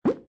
GUI_balloon_popup.ogg